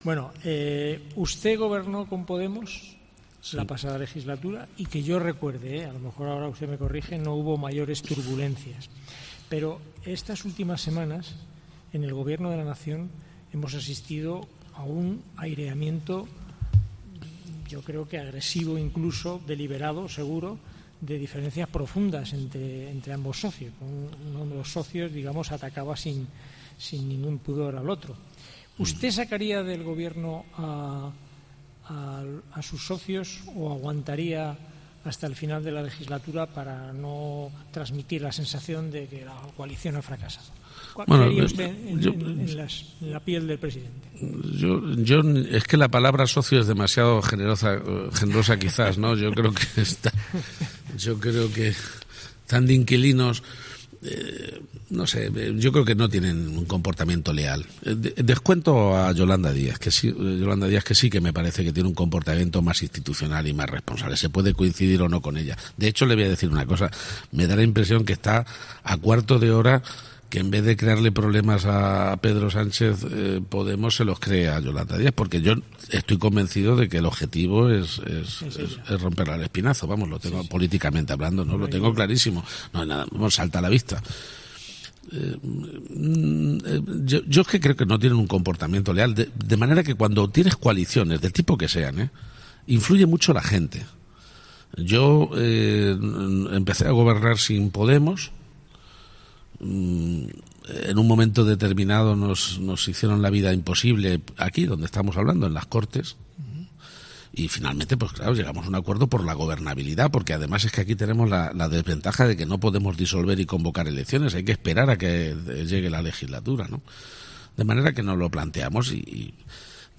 Emiliano García-Page fue el protagonista de este jueves en 'La Linterna' de COPE.